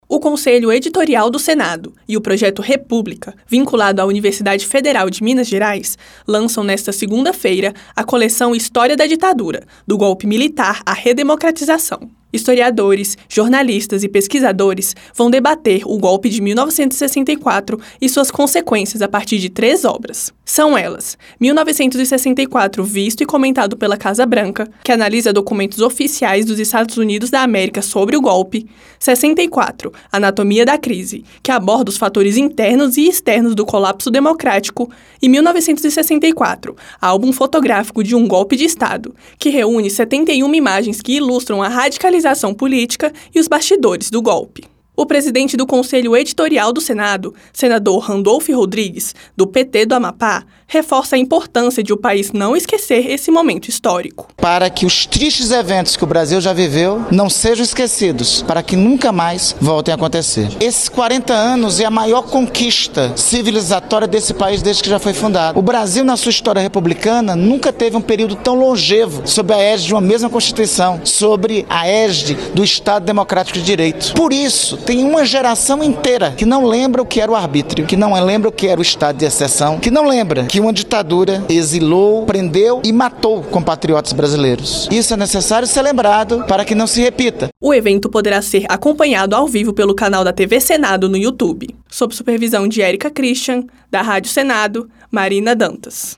Transcrição